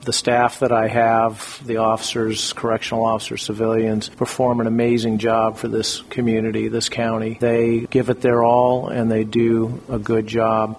Butler returned the favor, sharing his appreciation for the board and his own staff.
Butler-appreciation-of-staff.wav